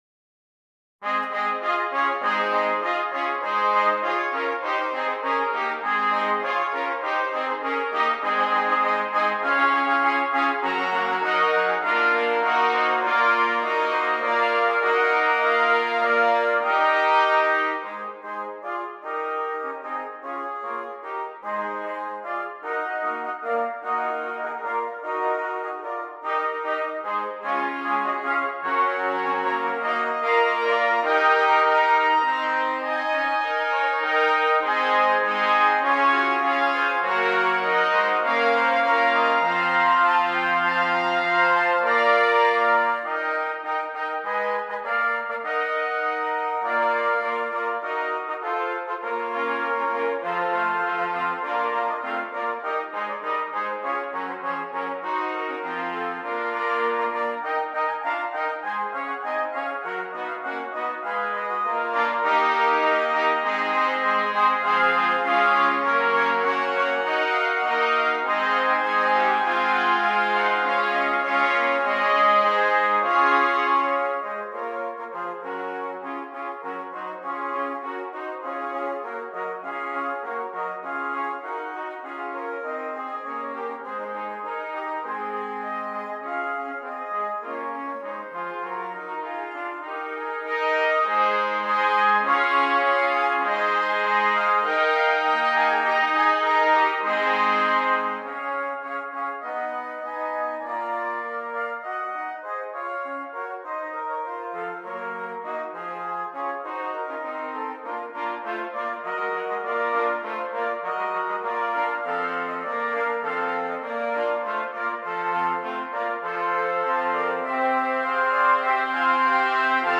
Brass
8 Trumpets
This arrangement is for 8 trumpets.